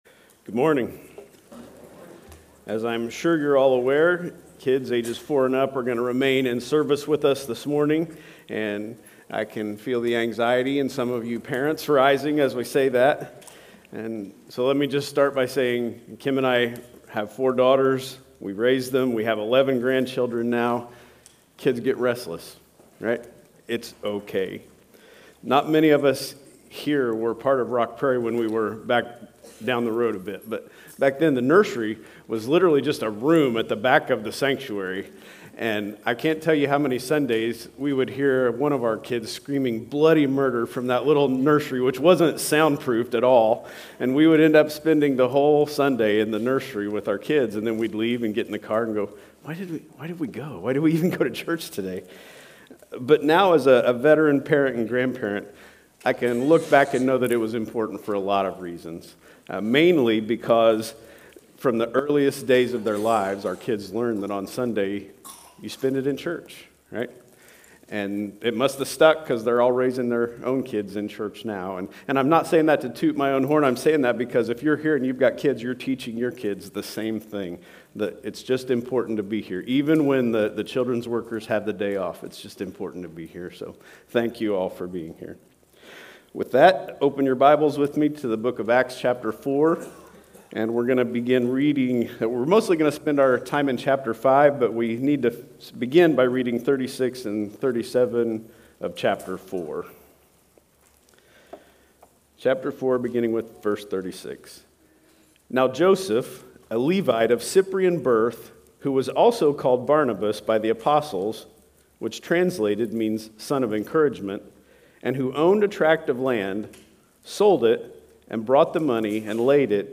3-30-25-Sunday-Service.mp3